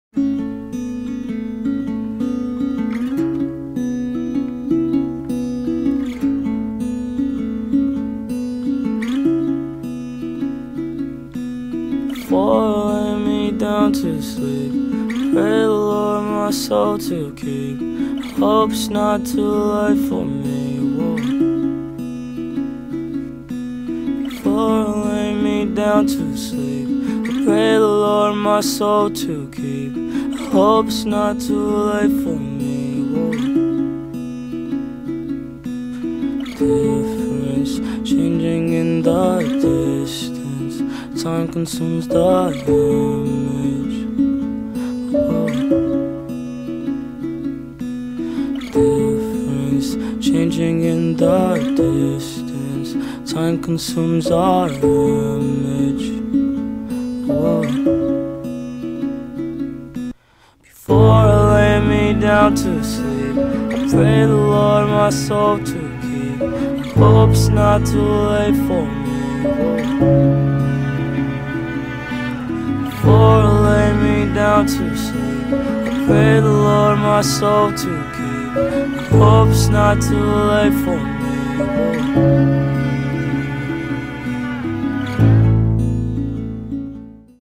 American Hip-hop Superstar